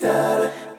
Presidential Vox 2.wav